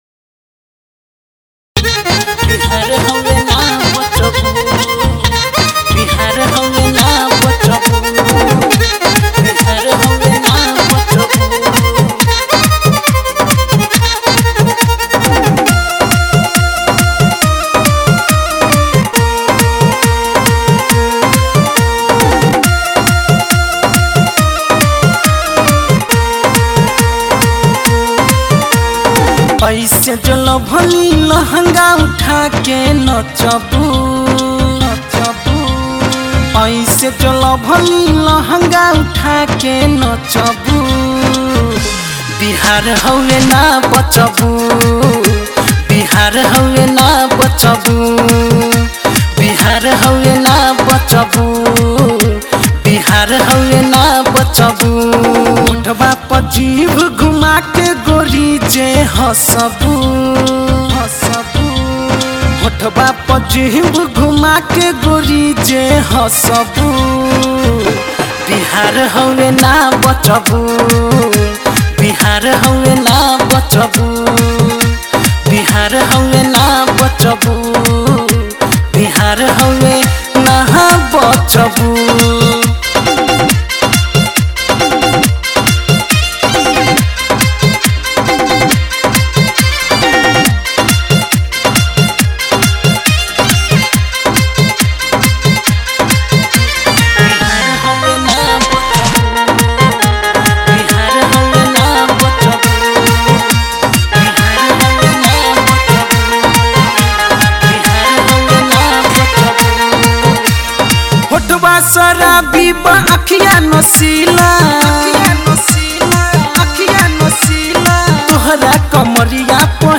bhojpuri lokgeet